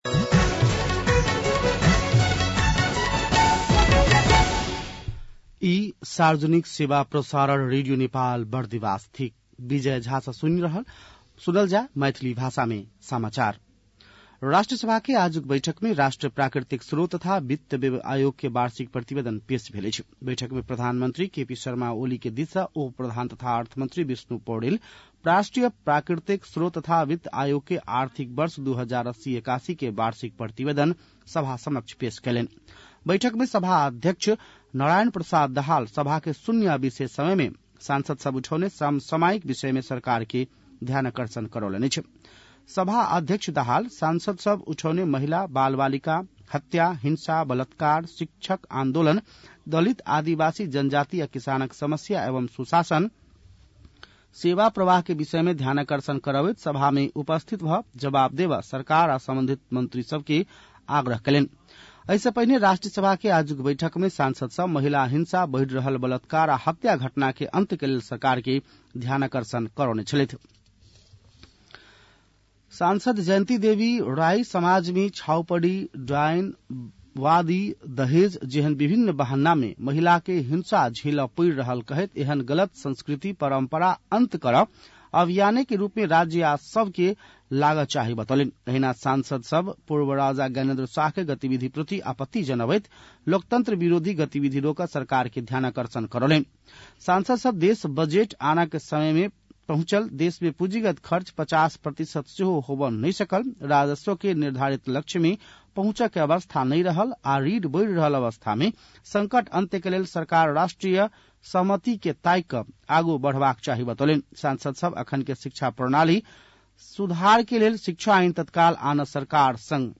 मैथिली भाषामा समाचार : २६ फागुन , २०८१